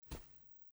在薄薄的积雪中轻轻的行走脚步单声－右声道－YS070525.mp3
通用动作/01人物/01移动状态/02雪地/在薄薄的积雪中轻轻的行走脚步单声－右声道－YS070525.mp3
• 声道 立體聲 (2ch)